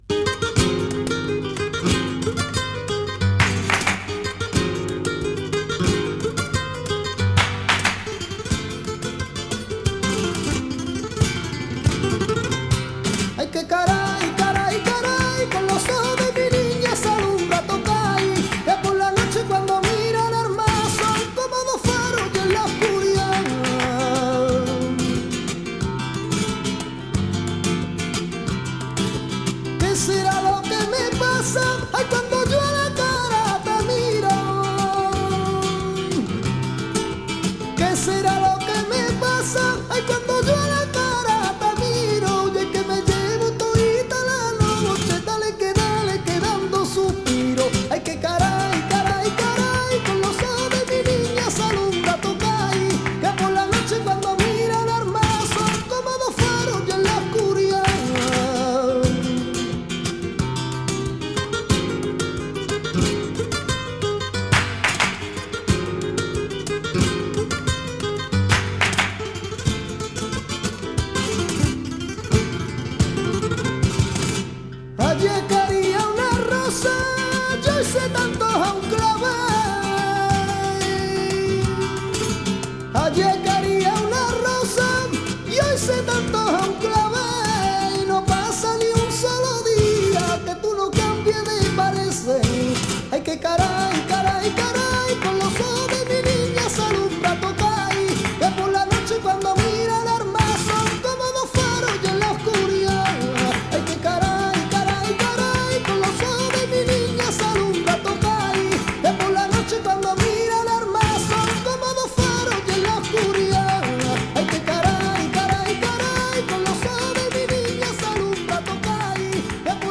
chant
guitare